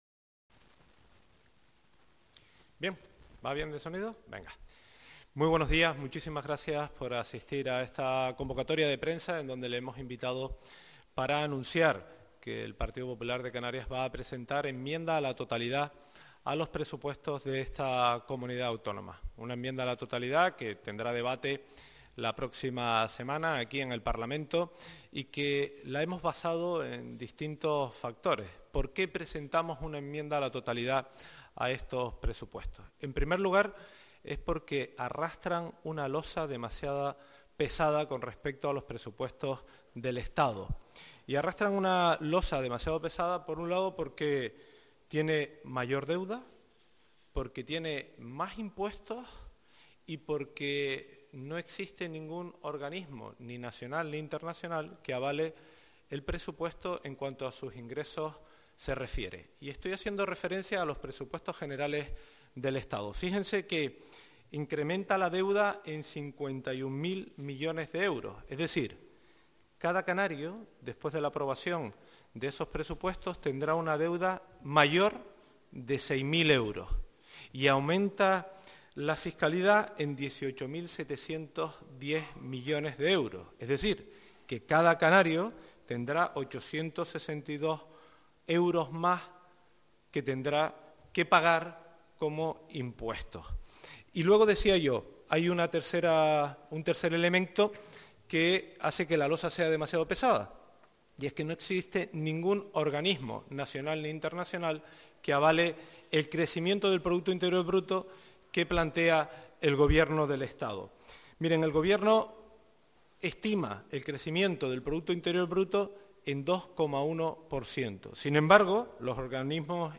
Rueda de prensa del GP Popular sobre valoración de los Presupuestos Generales de la Comunidad Autónoma de Canarias 2023 - 11:00